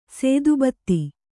♪ sēdu batti